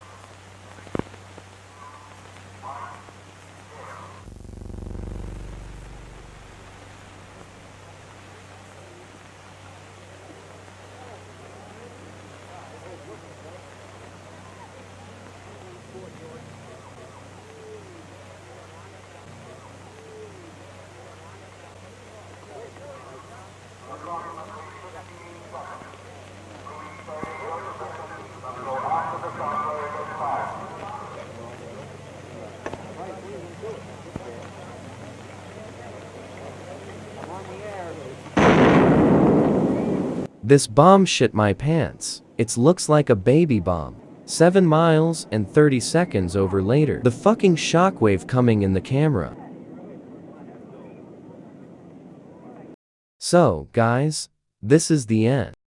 The f***ing shockwave effect coming